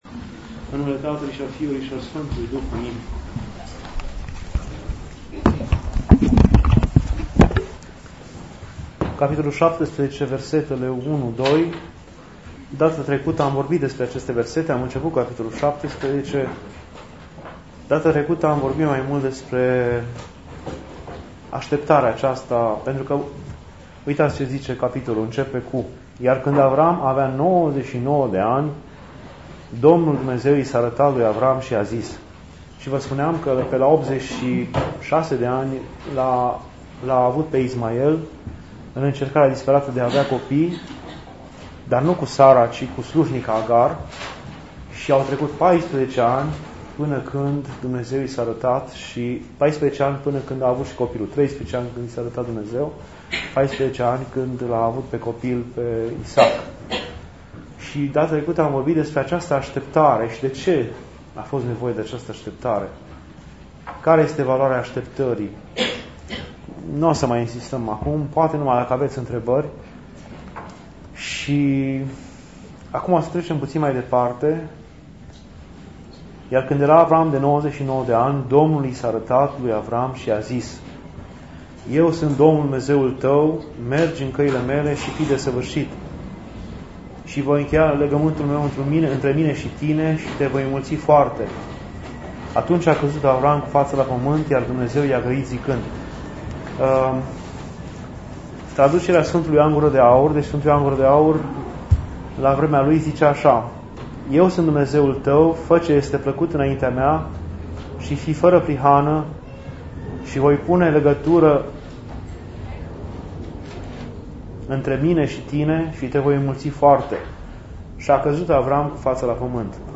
Dumnezeiasca Liturghie